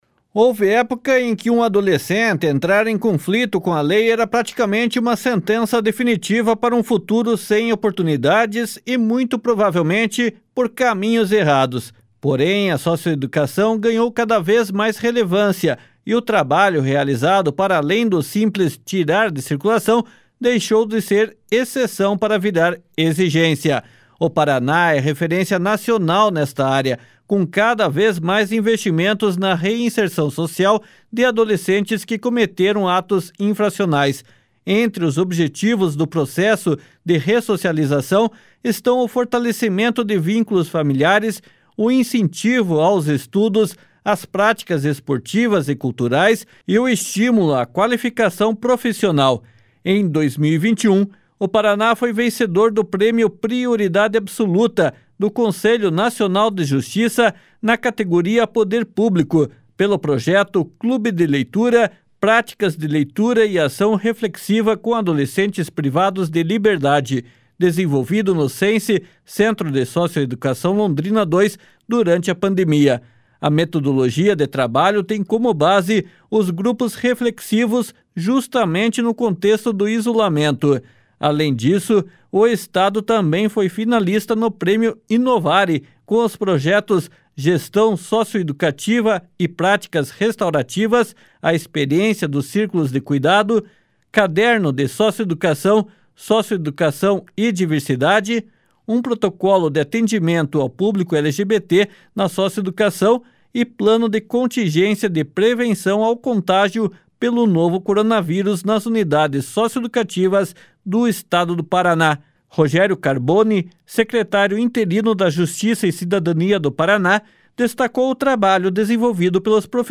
Rogério Carboni, secretário interino da Justiça e Cidadania do Paraná, destacou o trabalho desenvolvido pelos profissionais da socioeducação.
“Paraná, o Brasil que dá certo” é uma série de reportagens da Agência Estadual de Notícias.